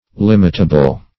Limitable \Lim"it*a*ble\ (l[i^]m"[i^]t*[.a]*b'l), a. Capable of being limited.